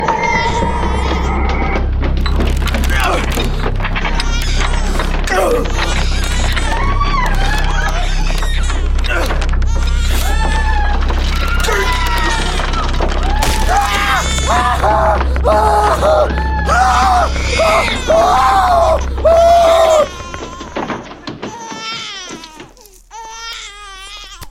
Звуки ужаса или для создания эффекта чего-то ужасного для монтажа видео
9. Ужасная сцена, крики людей, плач младенца